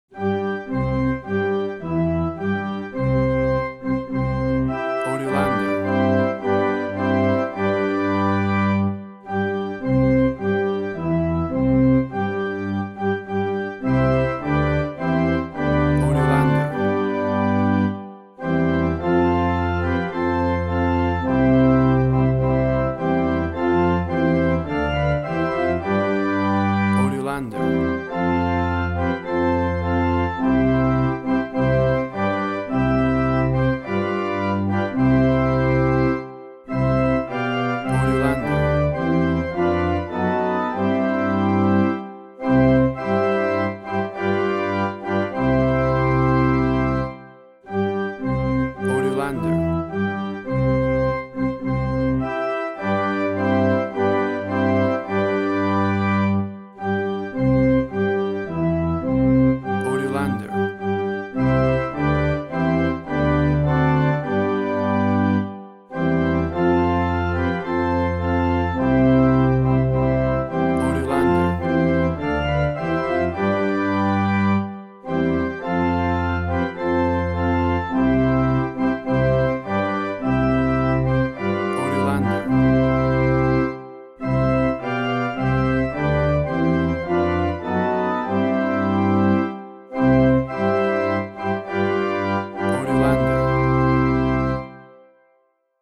A vibrant and heartwarming church organ version